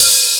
Tuned hi hats Free sound effects and audio clips
• Clean Open High-Hat Sound C Key 02.wav
Royality free open hi hat one shot tuned to the C note. Loudest frequency: 7323Hz
clean-open-high-hat-sound-c-key-02-qfS.wav